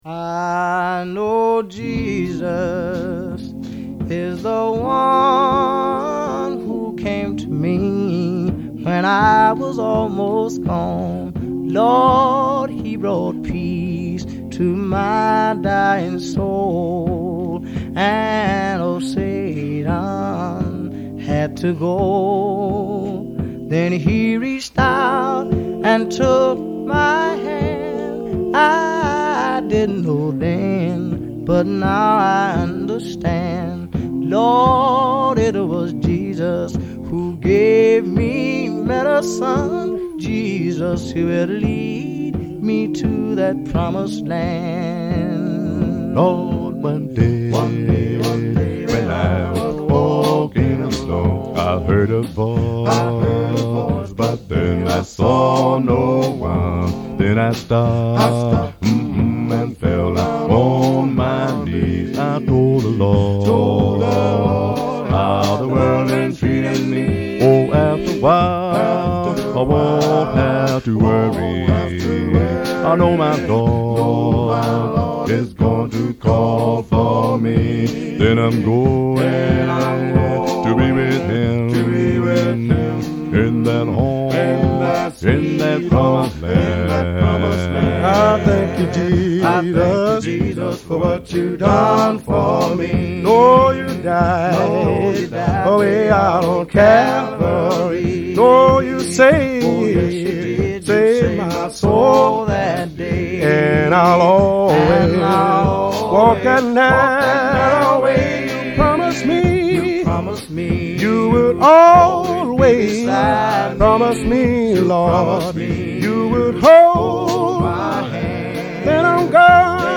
and for successfully managing a transition from gospel music
recorded in Hollywood
lead vocalist